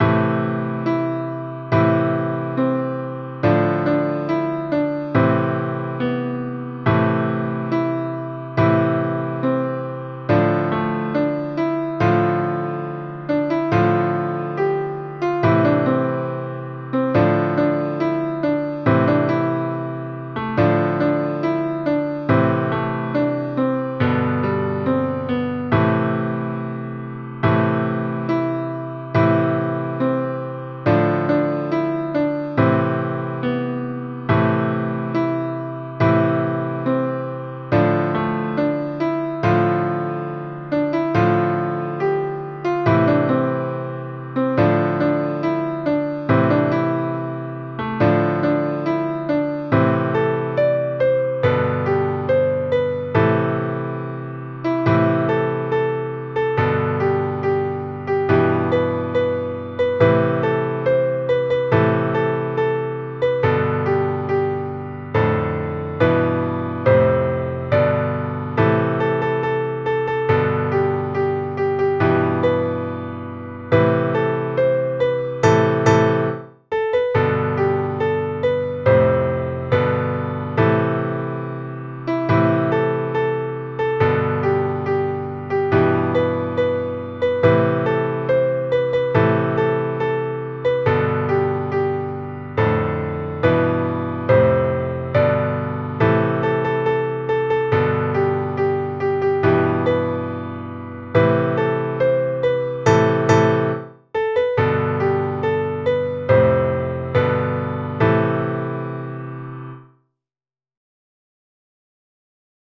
Jewish Folk Song
Piano Arrangement
A minor ♩= 70 bpm